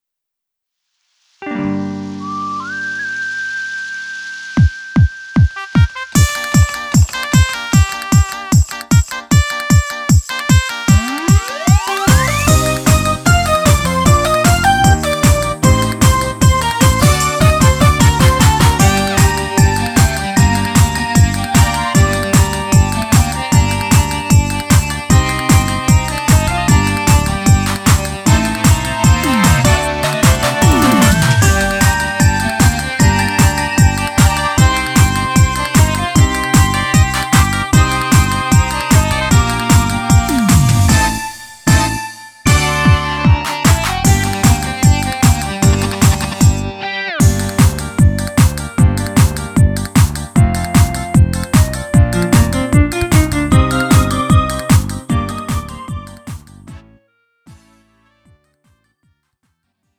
음정 원키 3:09
장르 가요 구분 Lite MR